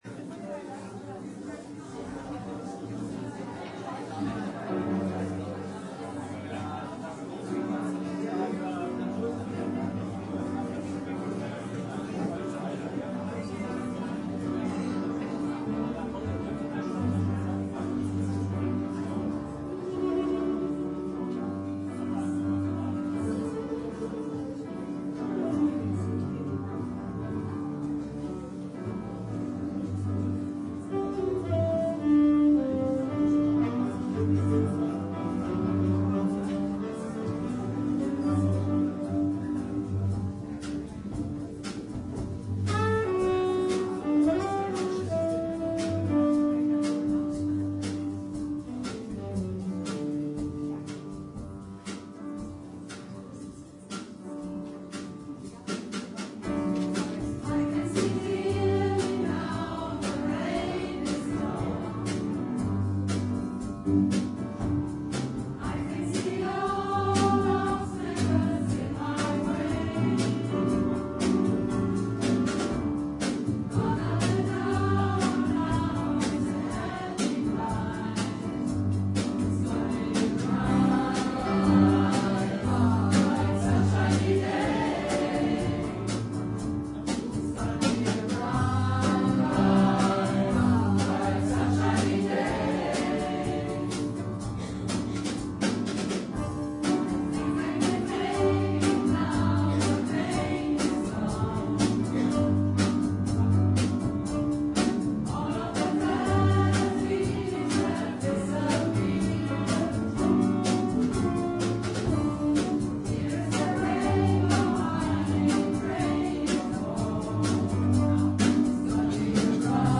für dreistimmigen Chor, Tenorsax und Schlagzeug